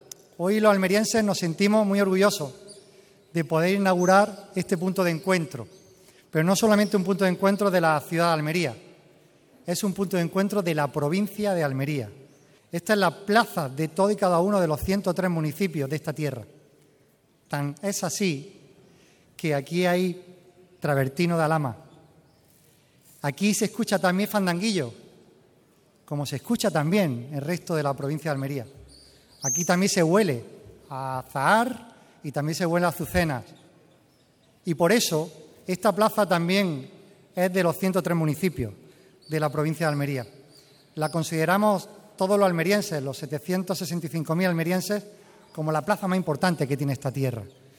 En la inauguración participan la alcaldesa, María del Mar Vázquez, el presidente de la Diputación, Javier A. García; la delegada de la Junta en Almería, Aránzazu Martín; y el subdelegado del Gobierno, José María Martín
13-06_inaugurac._plaza_vieja_javier_aureliano_garcia.mp3